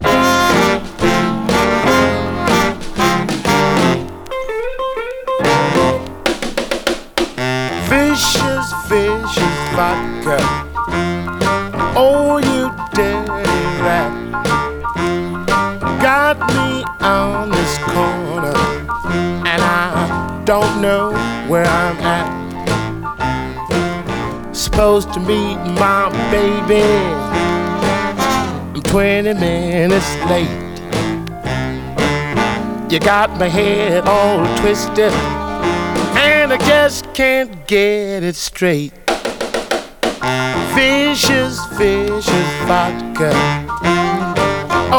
シンプルで小技の効いた演奏、個性を放つサックスにギター。
R&B, Jazz, Jump　France　12inchレコード　33rpm　Mono